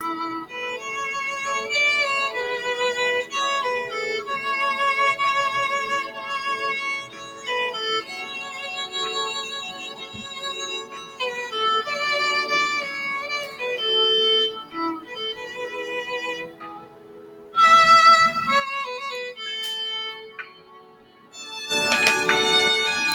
Instrumentos musicales
Violín Instrumento musical de cuerdas de origen italiano, este presenta cuatro cuerdas que al ser frotadas generan sonidos. Es uno de los instrumentos clásicos más antiguos y el más agudo de todos estos.